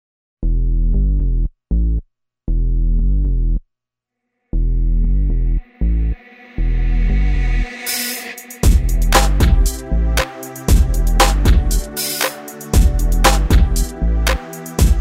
Dance, Techno